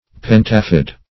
Search Result for " pentafid" : The Collaborative International Dictionary of English v.0.48: Pentafid \Pen"ta*fid\ (p[e^]n"t[.a]*f[i^]d), a. [Penta- + root of L. findere to split.]
pentafid.mp3